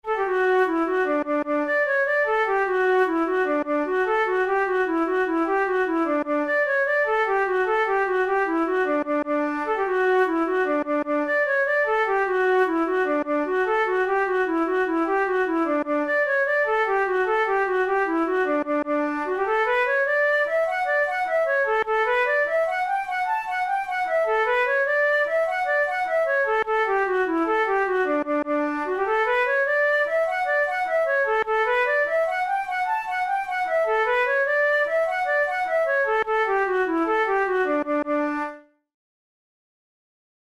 InstrumentationFlute solo
KeyD major
Time signature6/8
Tempo100 BPM
Jigs, Traditional/Folk
Traditional Irish jig